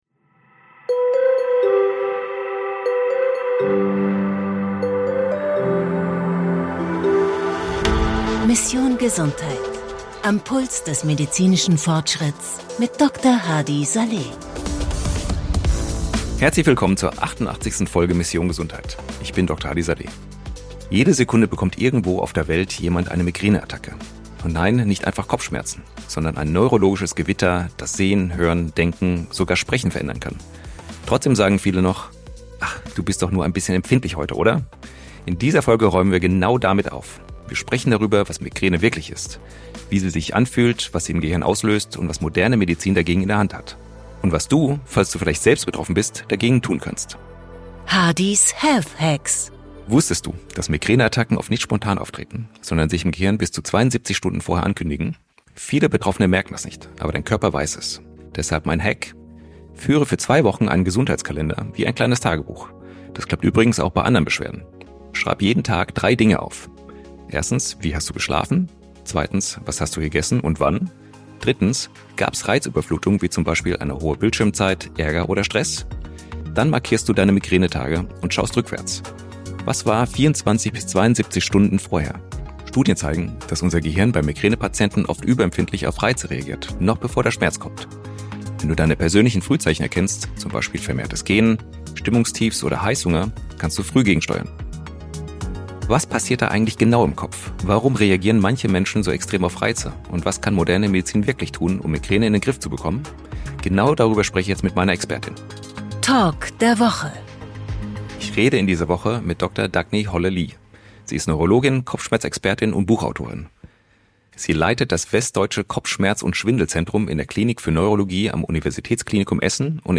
Fachärztin für Neurologie